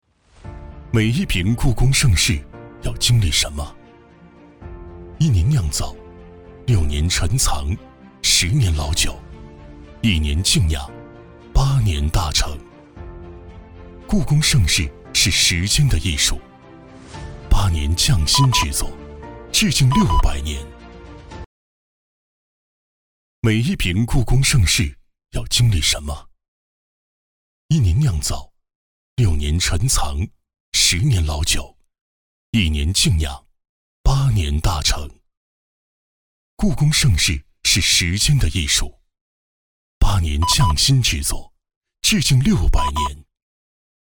淘声配音网站创立于2003年，是一家面向全国以互联网为平台的专业网络配音服务机构，汇集了服务于央视及各省卫视的大量优秀配音播音人才，拥有优秀的专业配音师，拥有自己专业的常规录音棚、角色棚等。
广告 故宫 酒类